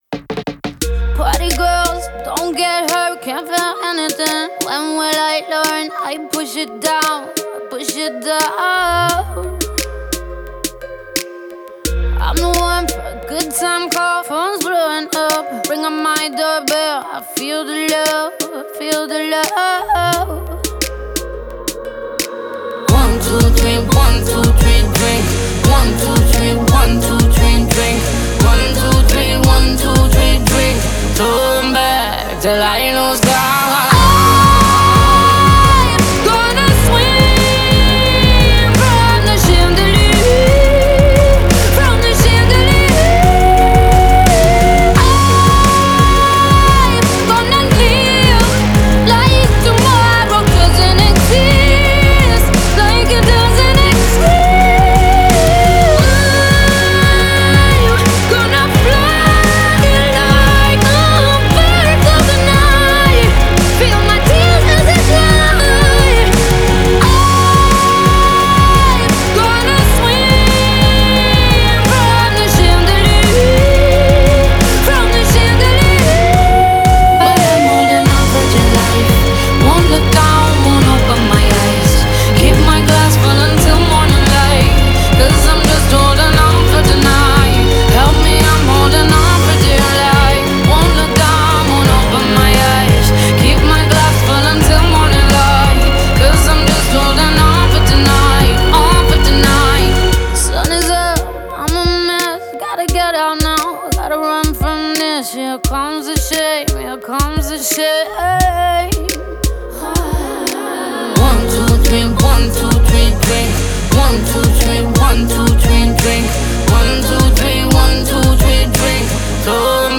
Pop, Electropop, Ballad